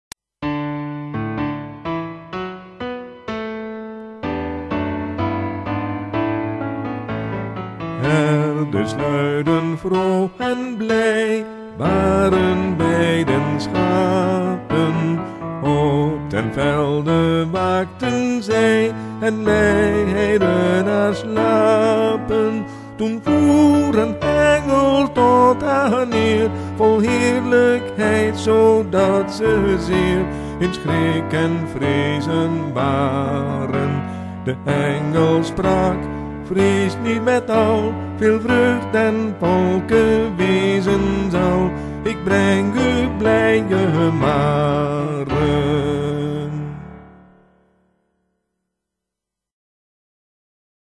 begeleiding